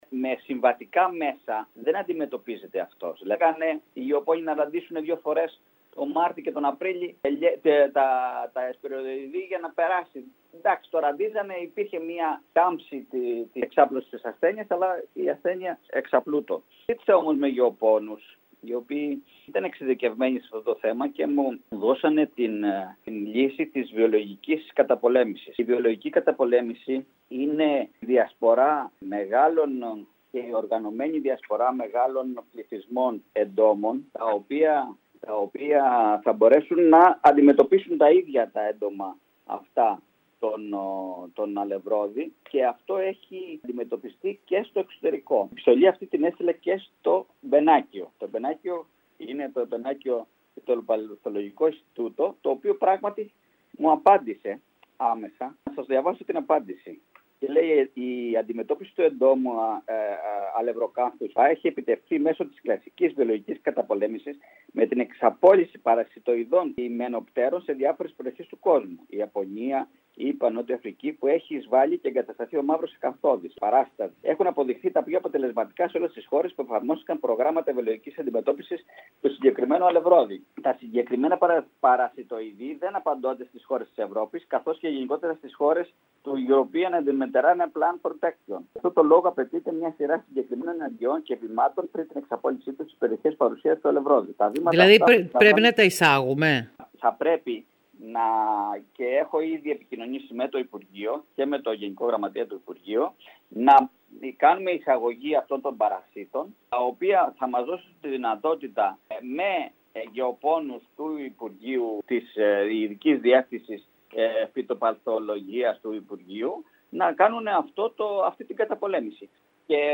Σήμερα μιλώντας στην ΕΡΑ ΚΕΡΚΥΡΑΣ ο αντιπεριφερειάρχης πρωτογενούς τομέα Σωτήρης Κουρής δήλωσε ότι έχει προχωρήσει ήδη αρκετά για την βιολογική αντιμετώπιση της ασθένειας.